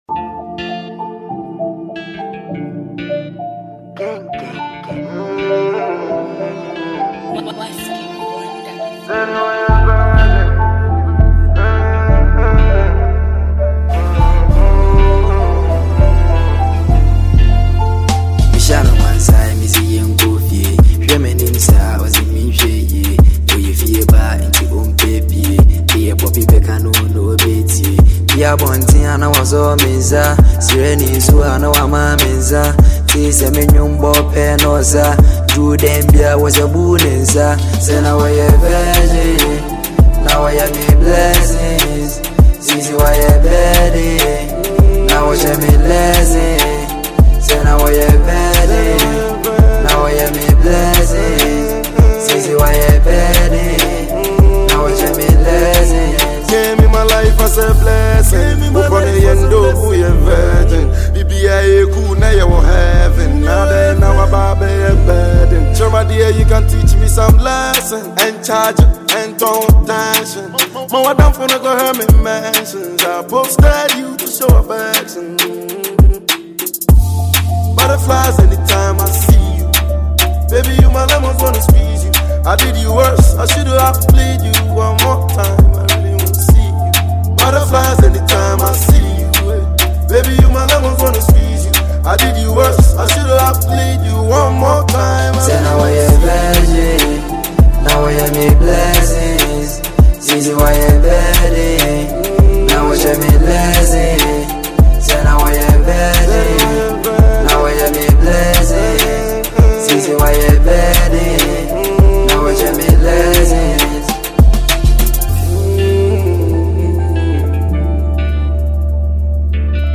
Asakaa recording artiste